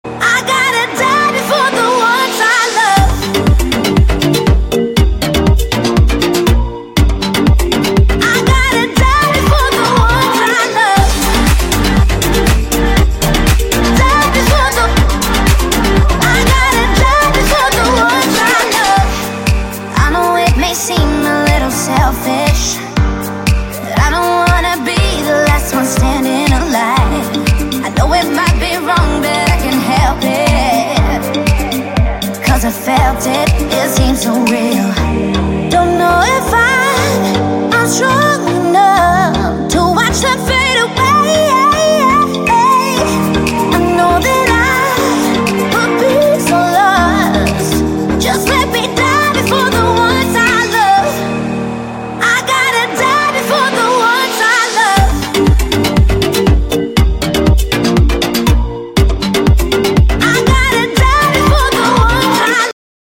• Качество: 128, Stereo
сингл австралийской певицы